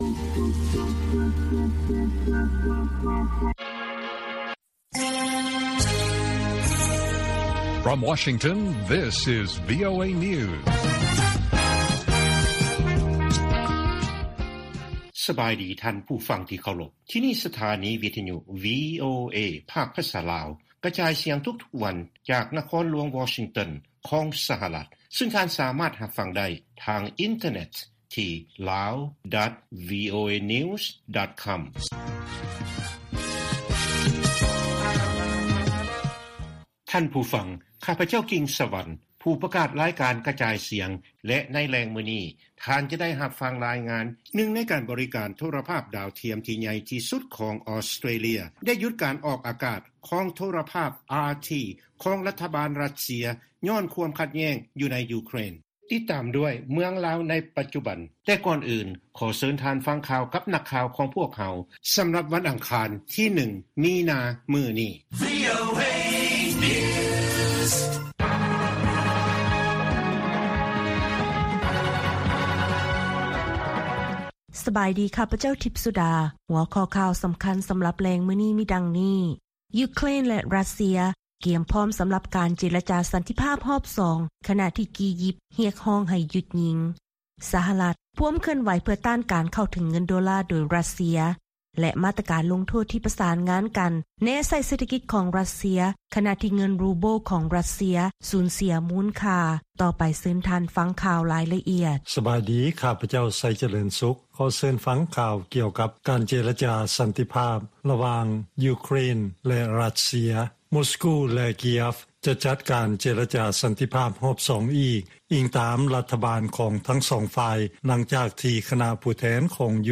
ລາຍການກະຈາຍສຽງຂອງວີໂອເອ ລາວ: ຢູເຄຣນແລະຣັດເຊຍ ກຽມພ້ອມສຳລັບ ການເຈລະຈາສັນຕິພາບຮອບສອງ ຂະນະທີ່ ກີຢິບ ຮຽກຮ້ອງໃຫ້ຢຸດຍິງ